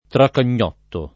tracagnotto [ trakan’n’ 0 tto ]